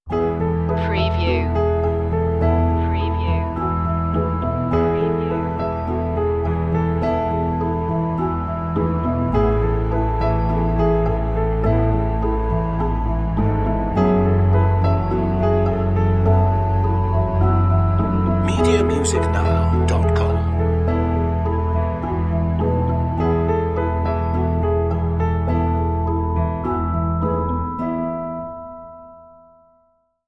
Short edit created from the main music track.